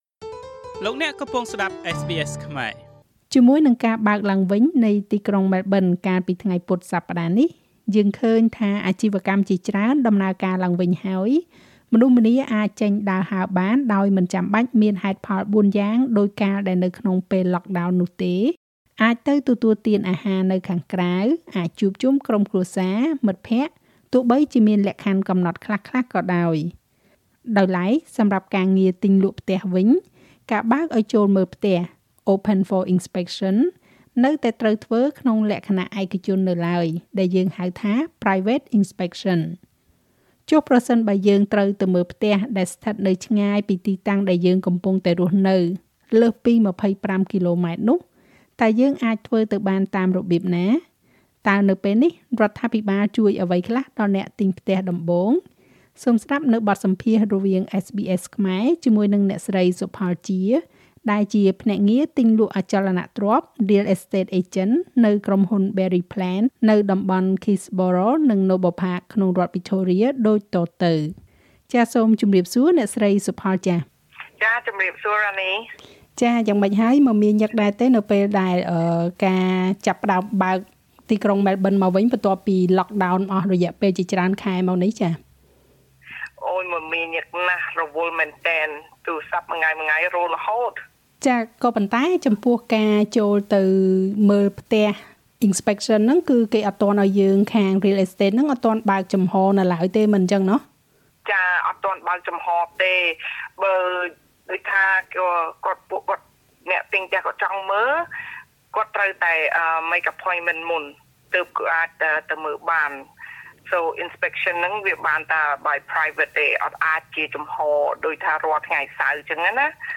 សូមស្តាប់បទសម្ភាន៍រវាងSBS ខ្មែរ